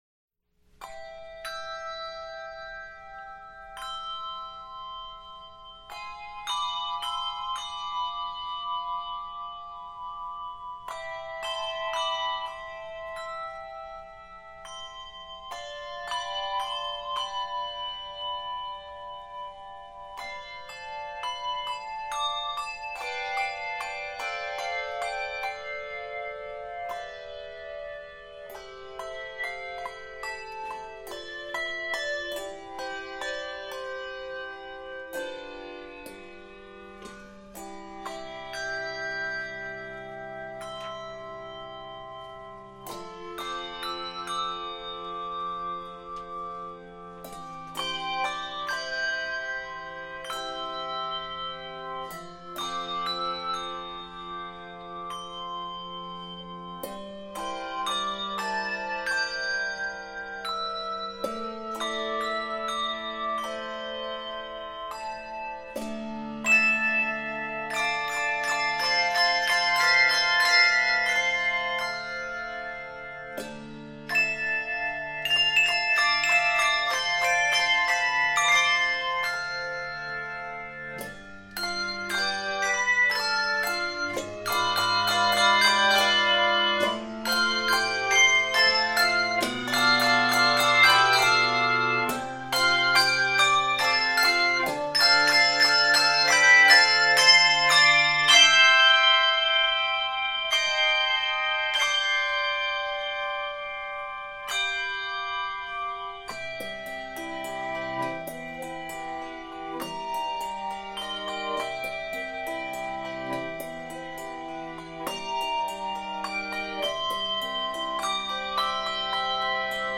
handbell setting